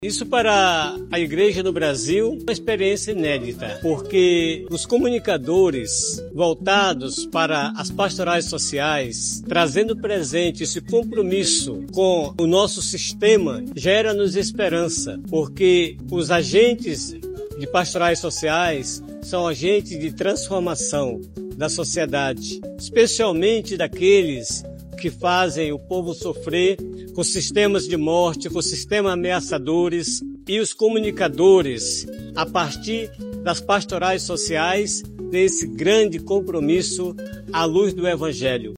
O 14º Mutirão Brasileiro de Comunicação (Muticom) aconteceu no Centro de Convenções Vasco Vasques, em Manaus, com o tema “Comunicação e Ecologia Integral: transformação e sustentabilidade justa”.
Dom José Altevir, bispo de Tefé (AM) e presidente do Conselho Pastoral dos Pescadores (CPP), ressaltou a importância da presença de comunicadores e comunicadoras das pastorais sociais da CNBB no Muticom.